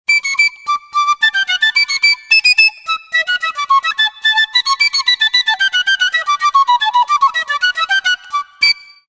PICCSOLO.mp3